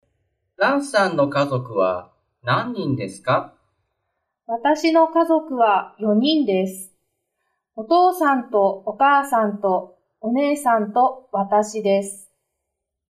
Thầy Trung và Lan đang nói chuyện với nhau về gia đình. Hãy nghe đoạn hội thoại xem gia đình Lan có mấy người?